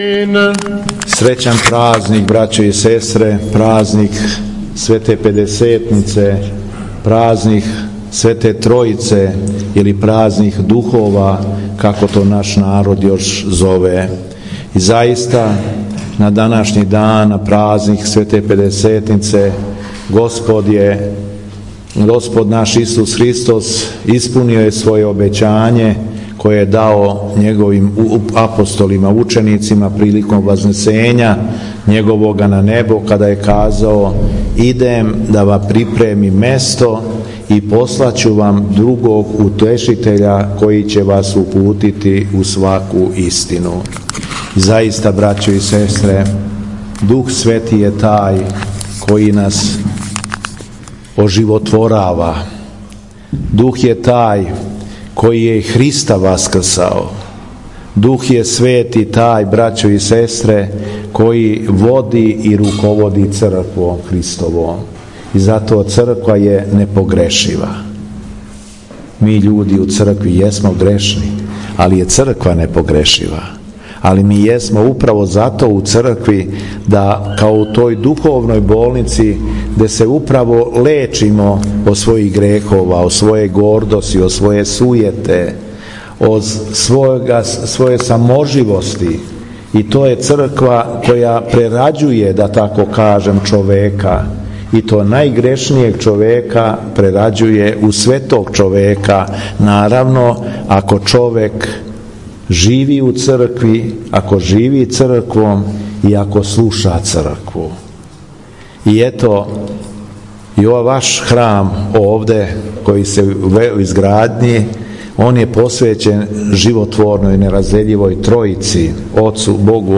У уторак трећег дана празника Свете Педесетнице 14. јуна 2022. године, у послеподневним часовима Његово Преосвештенство Епископ шумадијски Господин Јован посетио је Храм посвећен Светој Тројици у селу Блазнави.
Беседа Његовог Преосвештенства Епископа шумадијског г. Јована